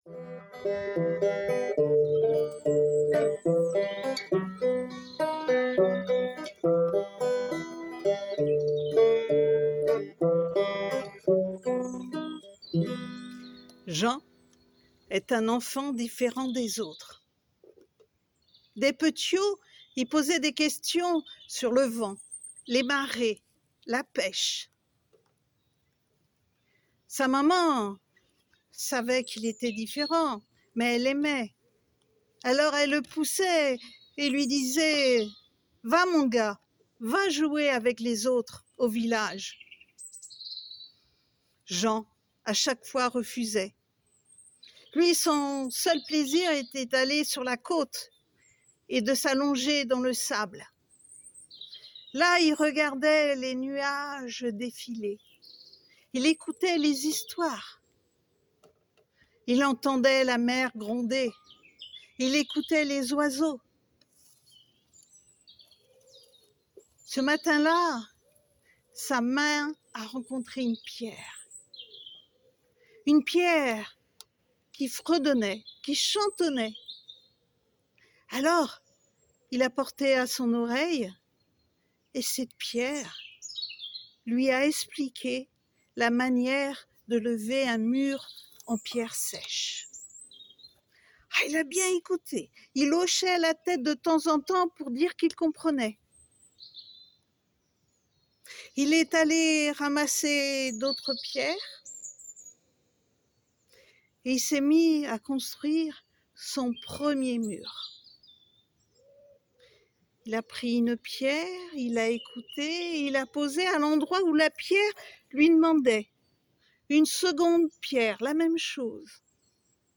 L’association Histoires de mots vous propose 3 contes audio pour une immersion totale dans les paysages vécus et contés de la presqu’île de Quiberon.